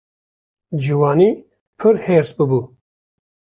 Uitgesproken als (IPA) /heːɾs/